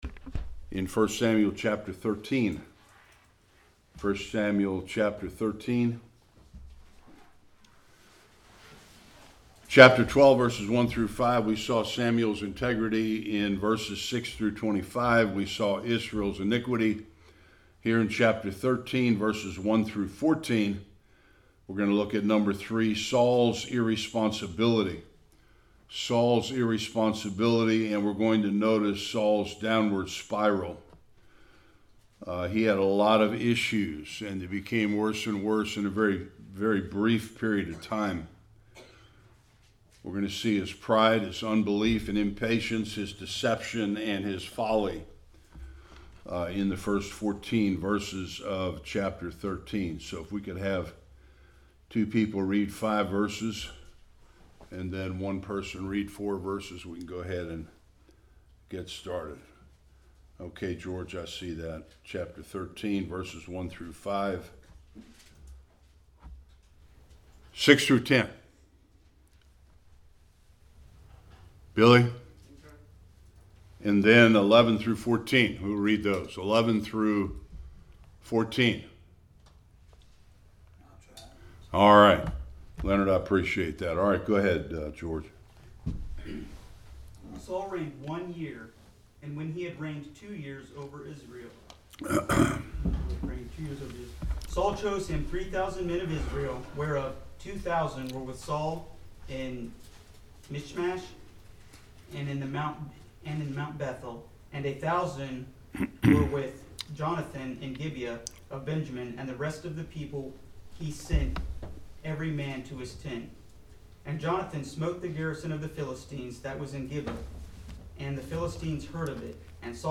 1-23 Service Type: Sunday School Saul continues his downward spiral.